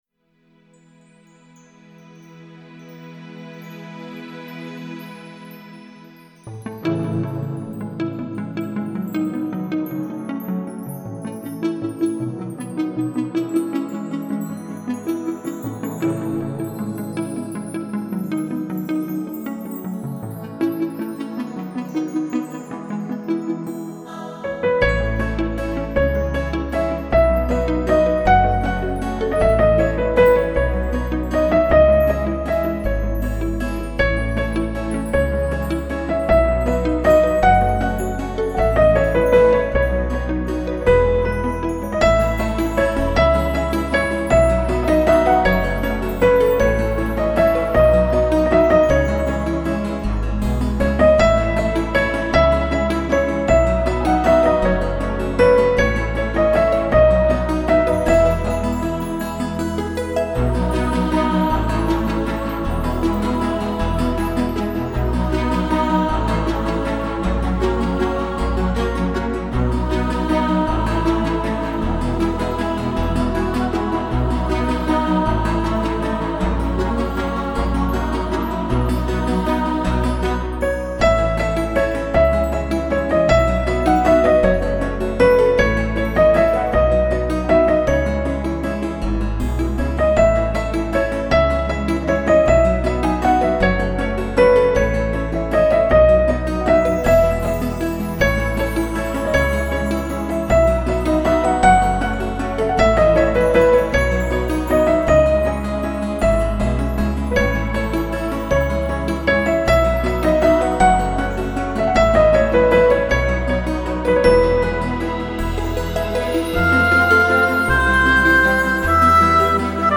搜集6大派，囊括世界音乐、新世纪、美声、名家钢琴、东方、古典